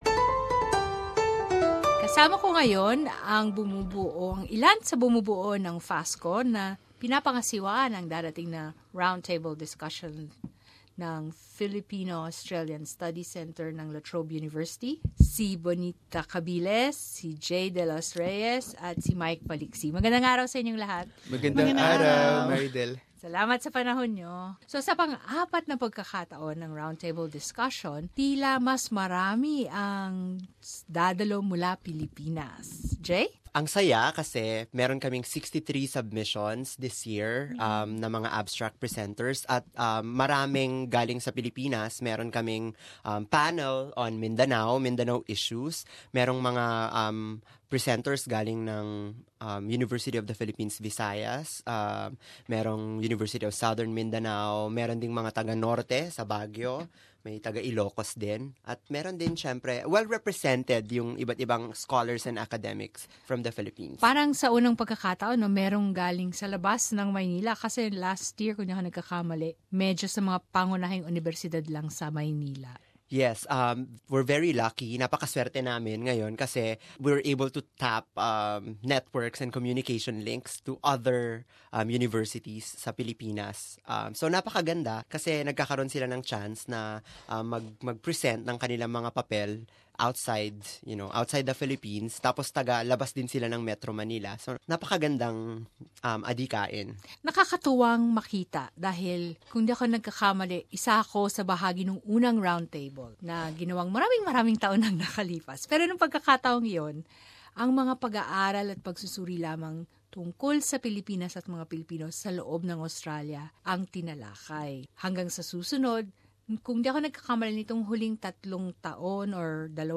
at SBS Studio, Federation Square Melbourne (SBS Filipino)